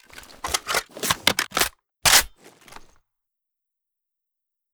ru556_reloadempty.ogg